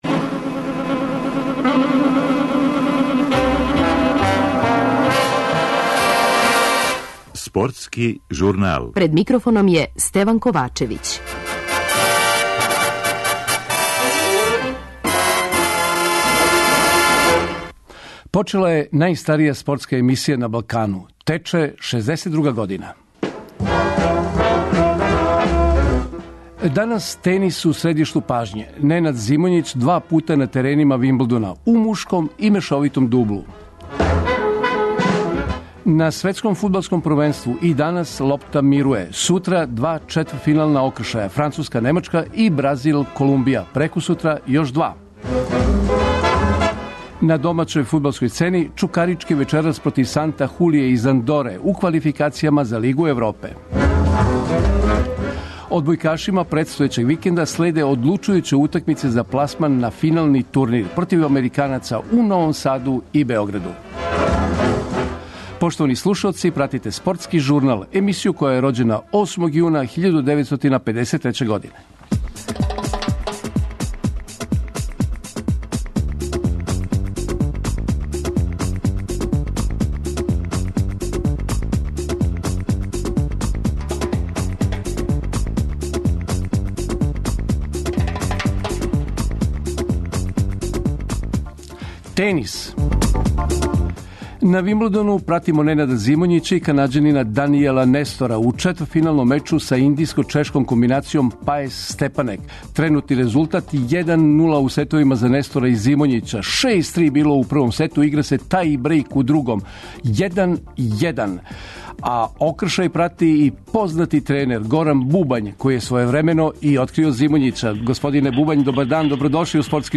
Радио Београд 1, 15.30